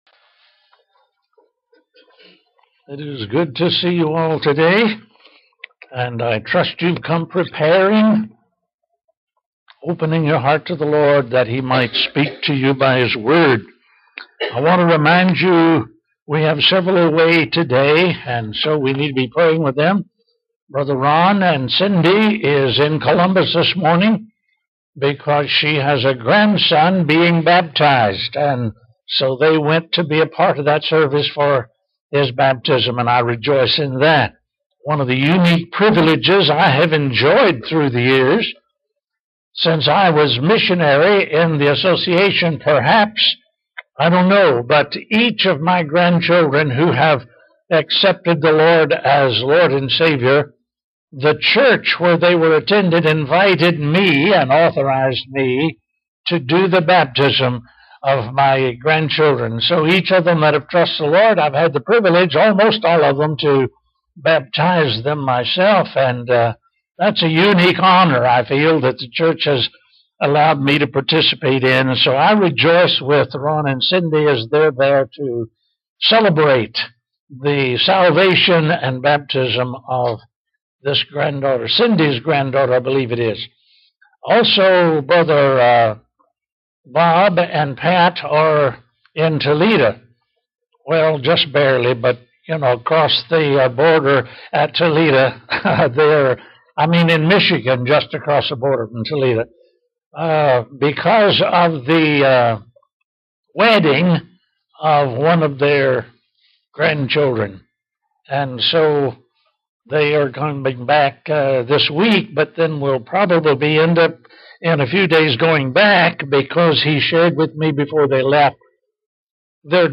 Sermons | Hillsdale Baptist Church